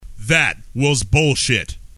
Tags: comedy announcer funny spoof crude radio